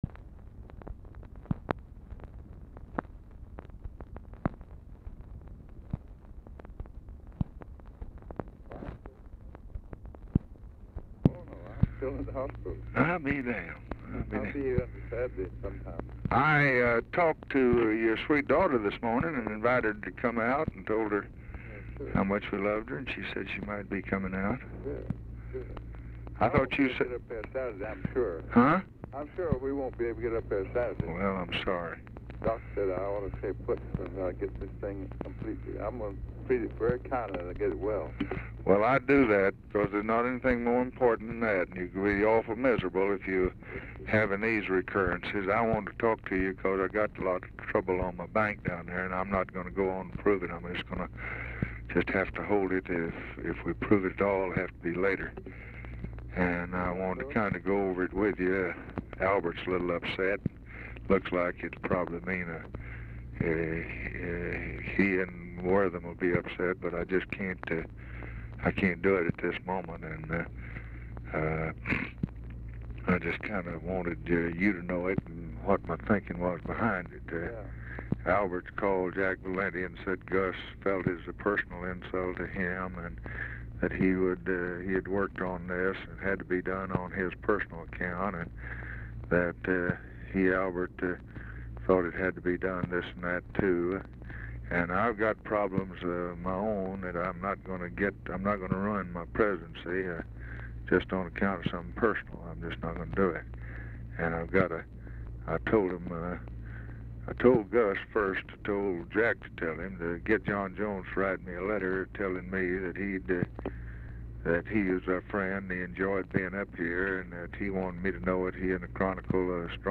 Telephone conversation
Dictation belt
LBJ Ranch, near Stonewall, Texas